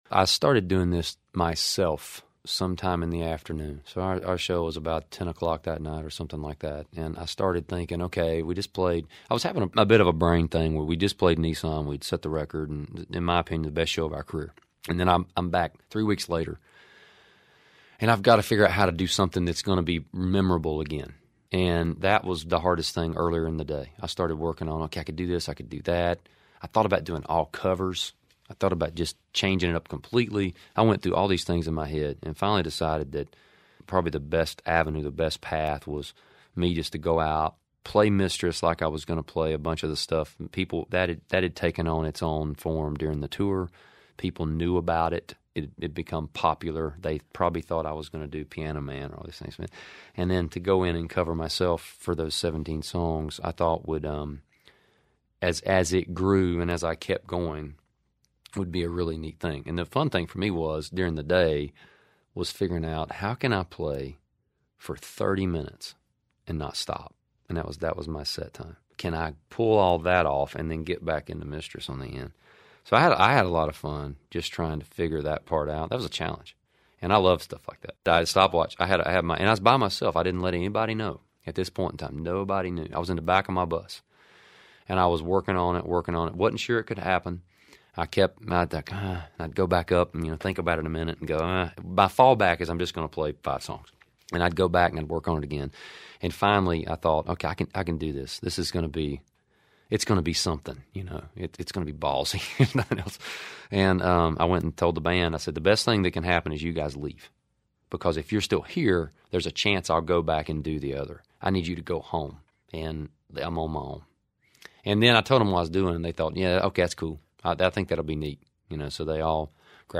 Audio / Eric Church explains how his epic 30-minute medley at CMA Music Fest came about.